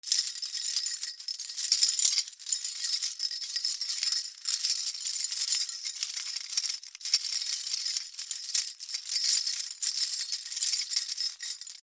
鍵束から鍵を探す 03
/ K｜フォーリー(開閉) / K35 ｜鍵(カギ)